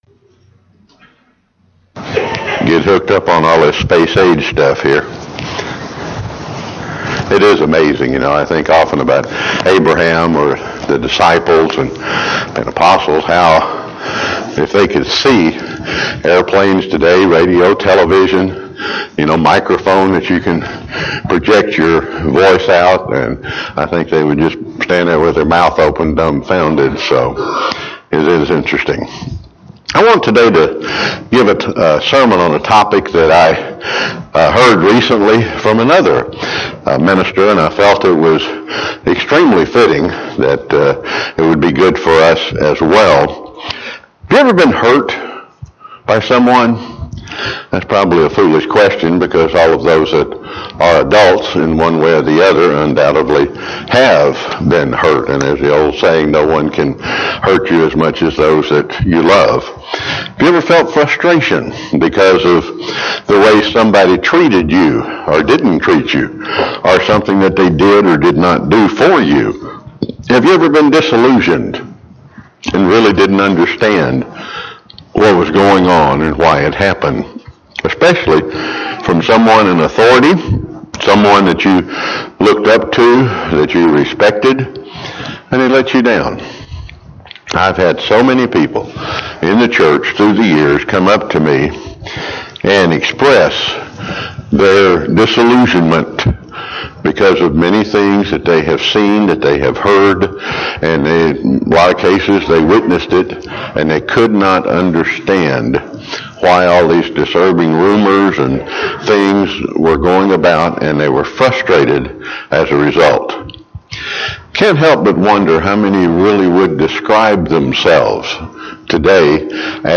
Sermons
Given in Chattanooga, TN